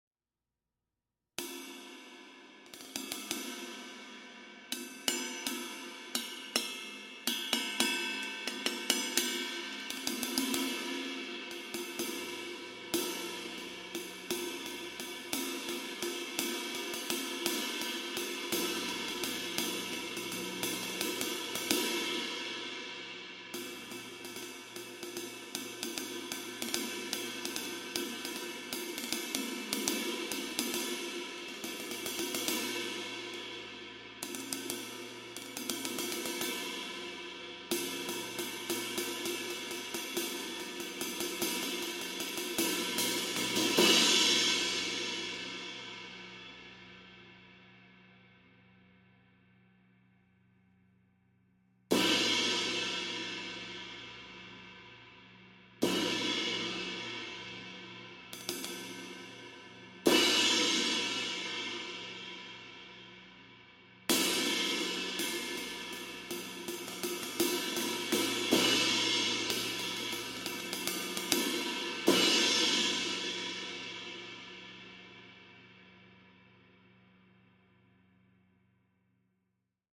We like to think that this combination of Custom Dry and Armor Brilliant might be like comparing it’s sound to the taste of salted caramel, or something sweet and sour, when you combine two opposing elements to create something special.
23″ Custom SE Ride Cymbal: Approx 2500 Grams
23_-Custom-Dry-SE-Ride.mp3